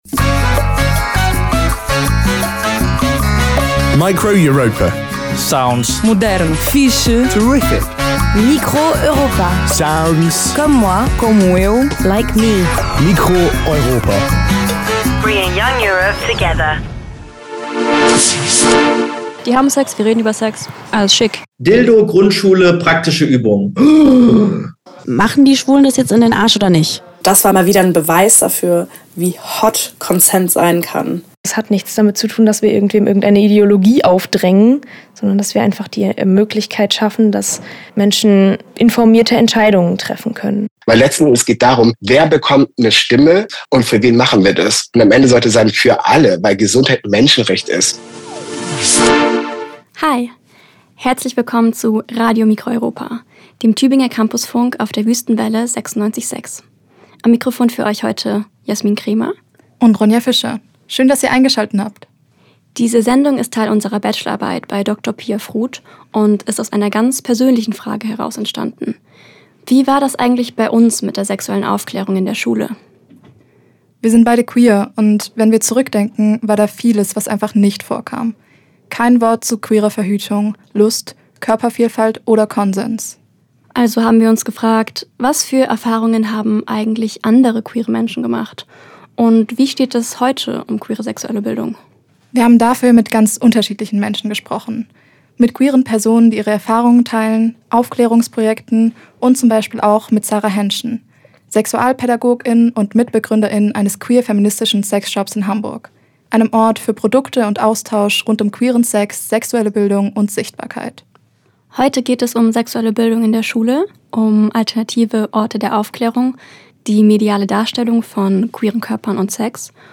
In der Radiosendung “Queere Sex Ed